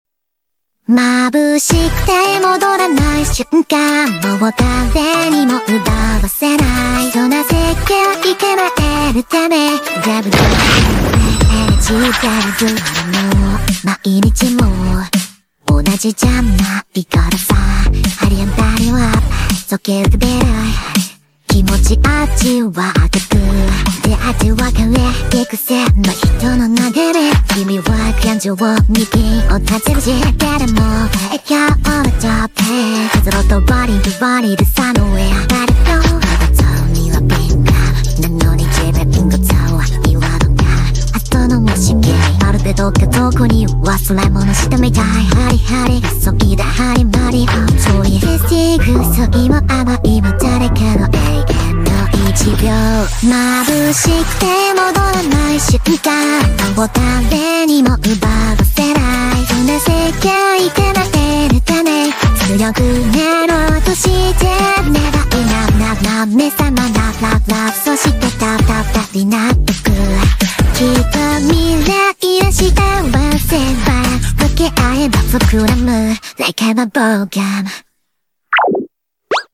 Ai Cover